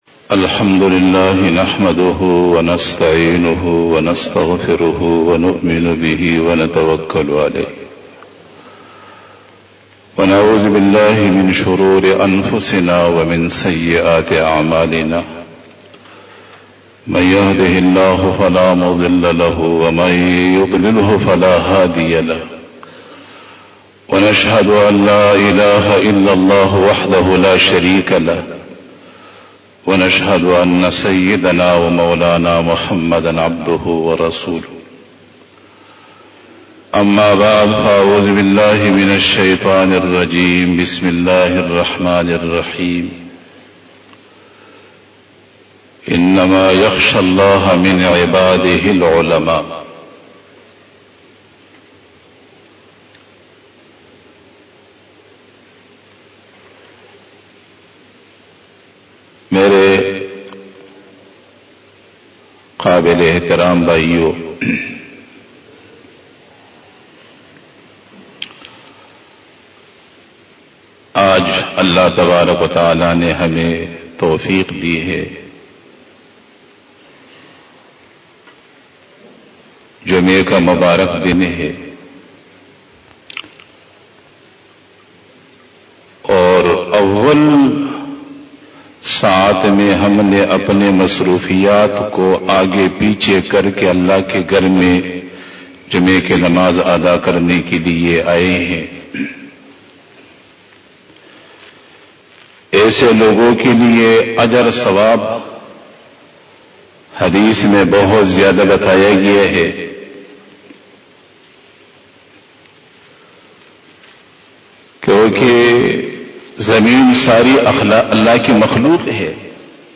03 BAYAN E JUMA TUL MUBARAK 15 Januay 2021 01 Jumadi us Sani 1442H)
Khitab-e-Jummah 2021